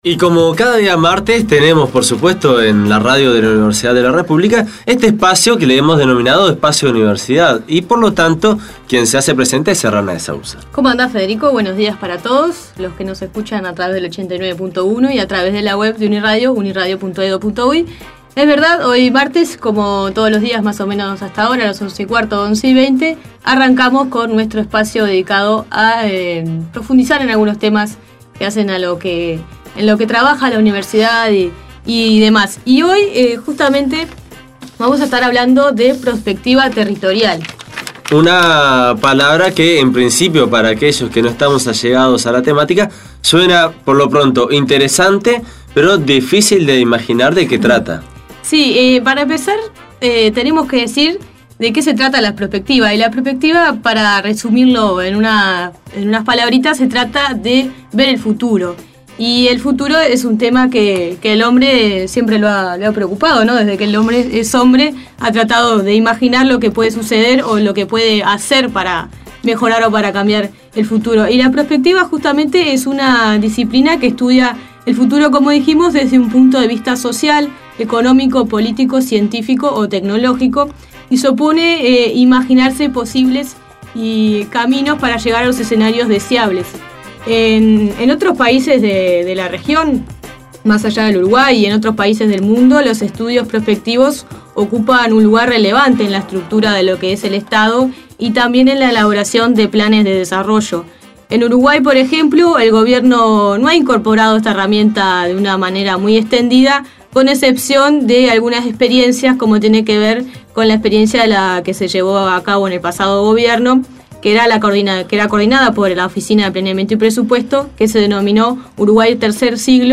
UNI Radio entrevistó